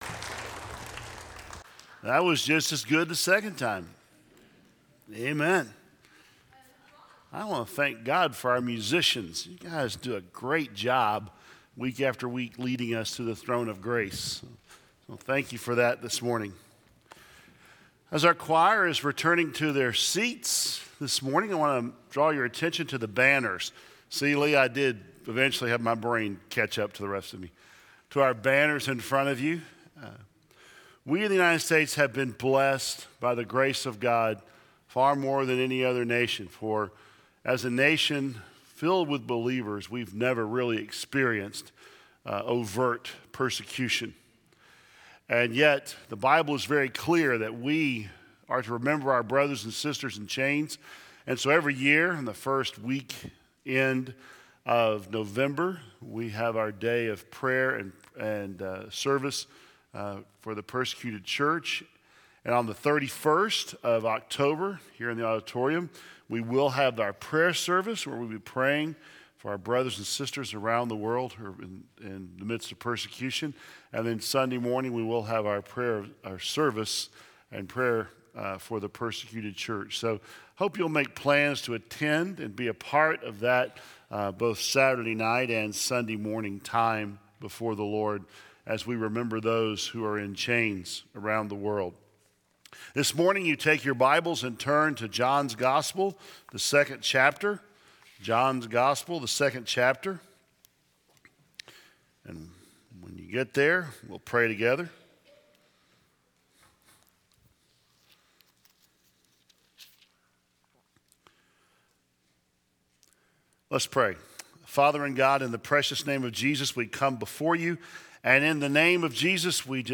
2015 Related Share this sermon